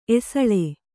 ♪ esaḷe